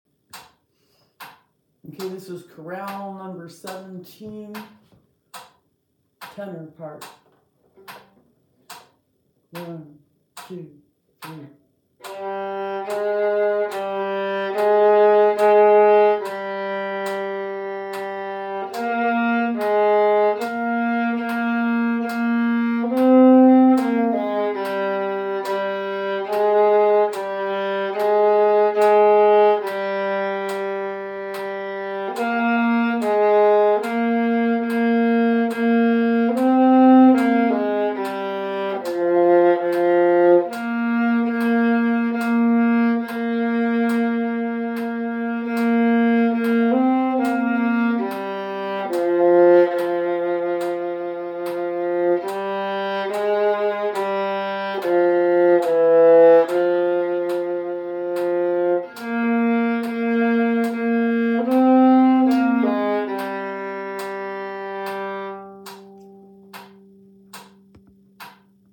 Chorale No. 17, Tenor